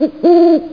OWL1.mp3